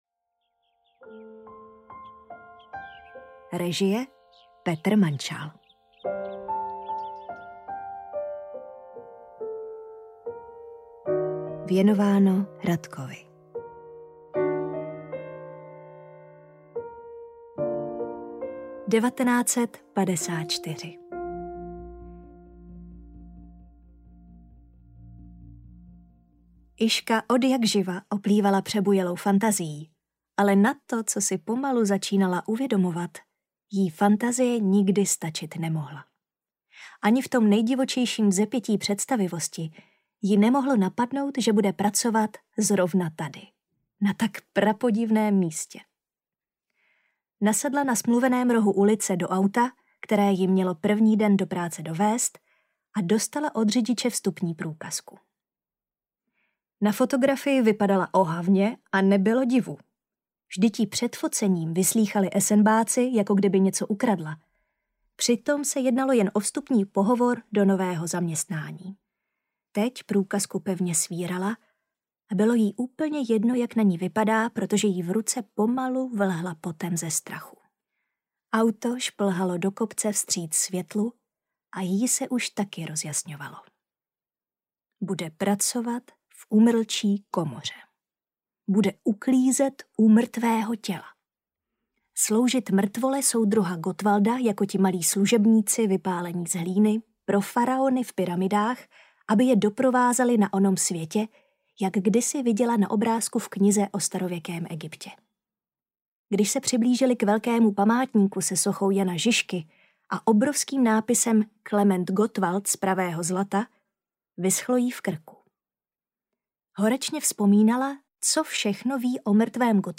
Ukázka z knihy
gottwaldova-mumie-audiokniha